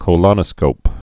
(kō-lŏnə-skōp, kə-)